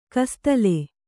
♪ kastale